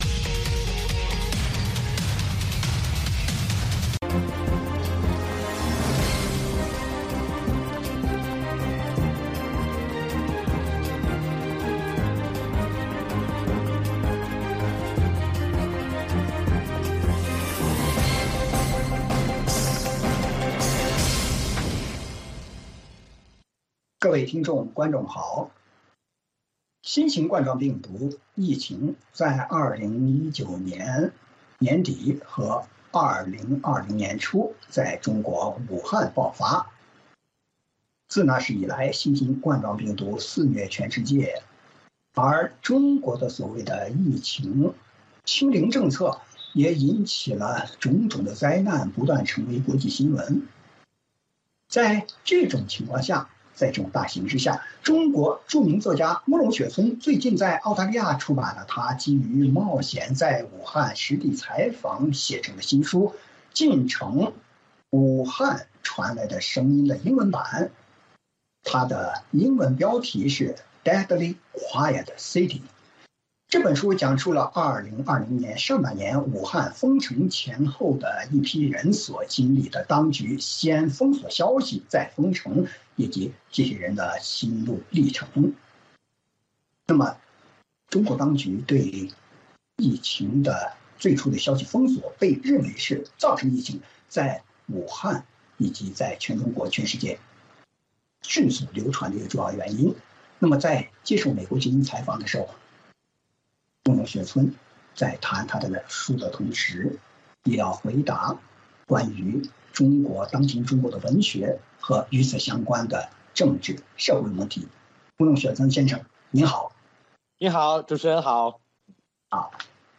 VOA卫视-时事大家谈 专访《禁城：武汉传来的声音》作者慕容雪村：“希望向全世界讲述中国人活在一个什么样的制度之下”（完整版）